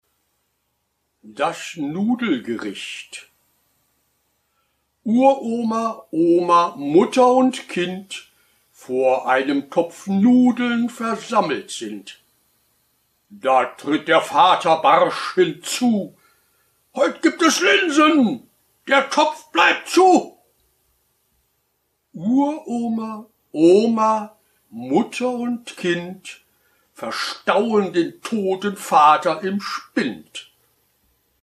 Ballade Erlkönig von Johann Wolfgang von Goethe Rezitation: Erlkönig